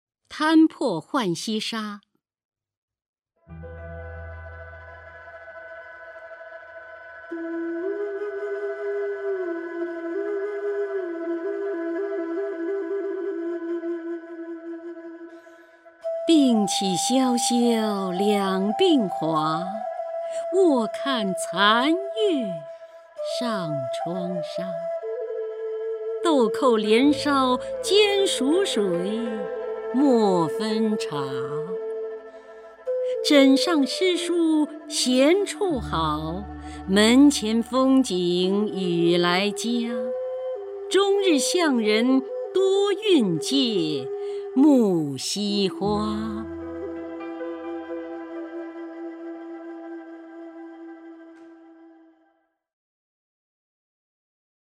姚锡娟朗诵：《摊破浣溪沙·病起萧萧两鬓华》(（南宋）李清照)　/ （南宋）李清照
名家朗诵欣赏 姚锡娟 目录
TanPoHuanXiShaBingQiXiaoXiaoLiangBinHua_LiQingZhao(YaoXiJuan).mp3